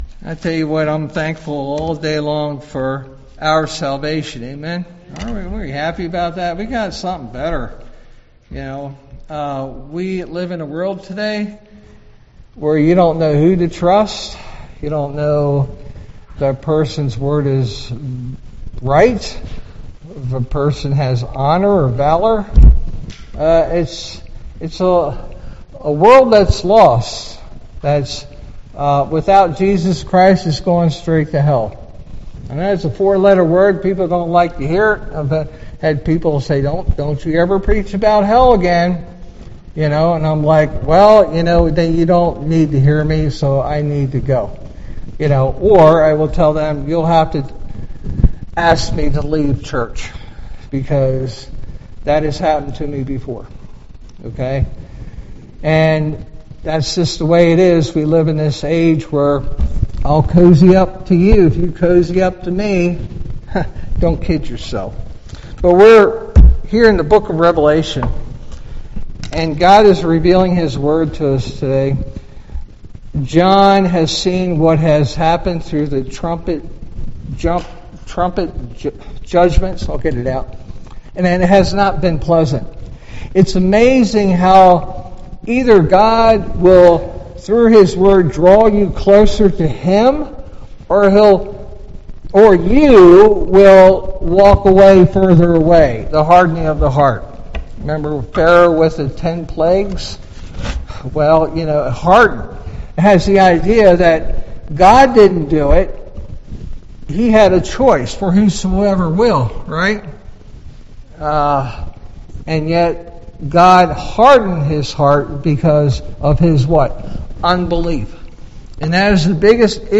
All Sermons The 7th Seal and the 4 Trumpets 12 April 2026 Series